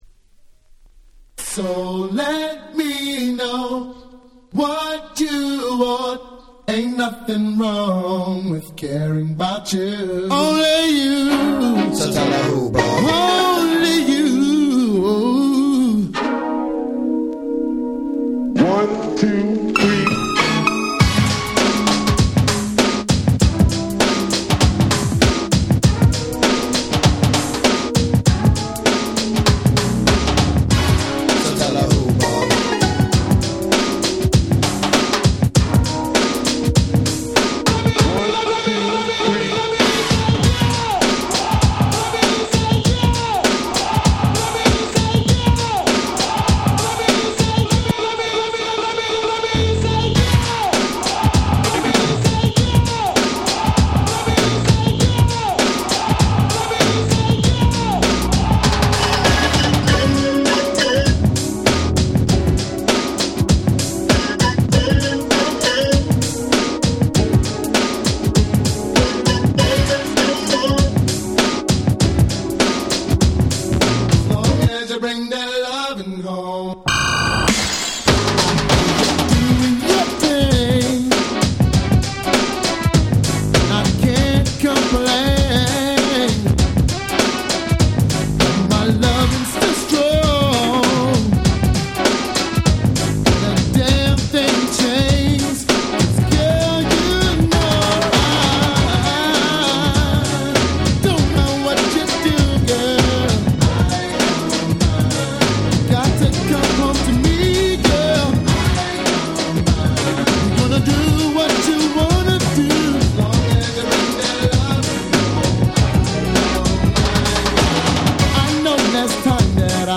92' Super Nice R&B !!
ずっしりしたハネたBeatに甘い男性Vocalの最強R&B / New Jack Swing !!
ビッグバブ 90's ニュージャックスウィング ハネ系